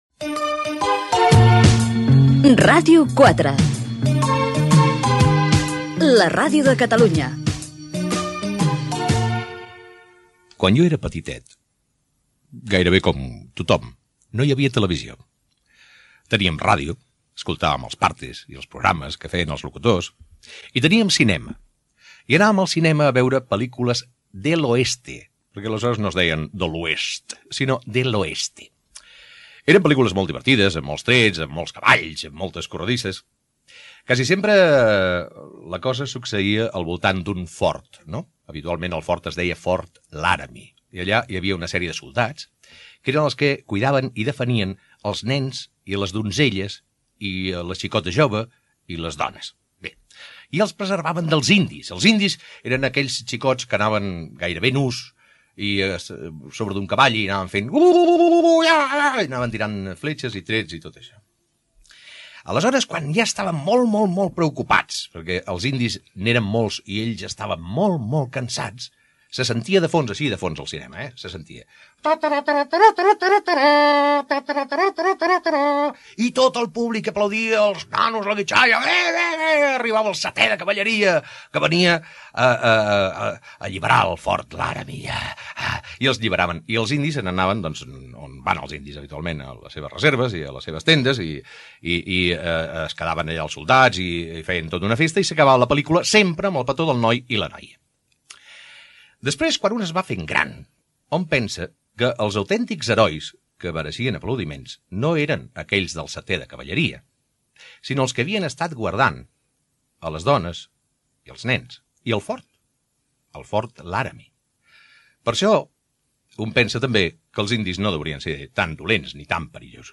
ec630ef220bb374c47c15d6785b7dcd00bc322c5.mp3 Títol Ràdio 4 Emissora Ràdio 4 Cadena RNE Titularitat Pública estatal Nom programa L'autobús Descripció Primera edició del programa. Indicatiu de la ràdio, record de quan Jordi Estadella era petitet, indicatiu del programa, presentació, sumari de continguts, indicatiu del programa, telèfon i invitació a la participació, tema musical, hora, trucades telefòniques, hora
Entreteniment